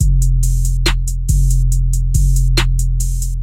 描述：808 Trap鼓循环
Tag: 140 bpm Trap Loops Drum Loops 590.80 KB wav Key : F